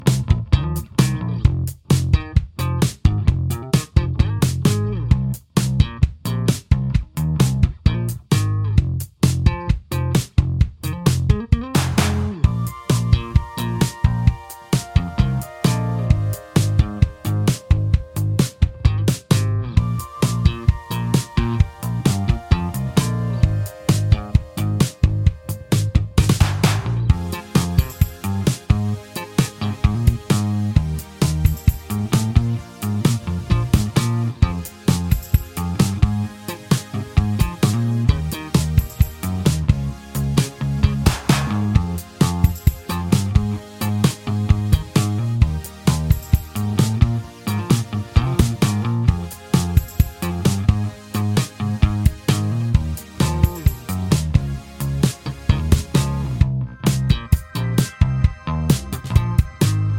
Minus Main Guitars For Guitarists 4:25 Buy £1.50